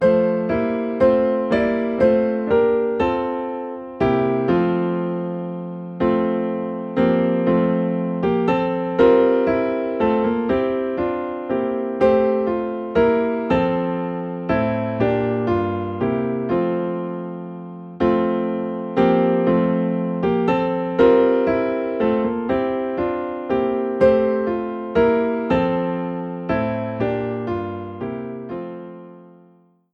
Faithful Piano Accompaniments for Worship